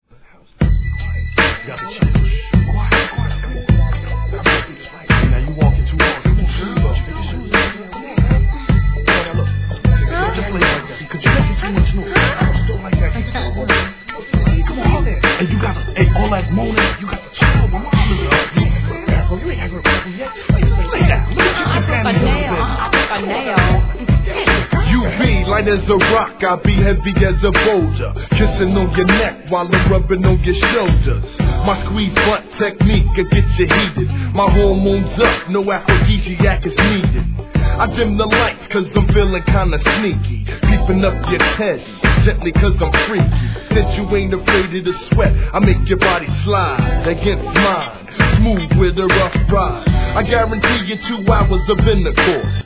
HIP HOP/R&B
ゆったりとしたBEATにあえぎ声がアクセントの1995年、N.Y.アンダーグランド!!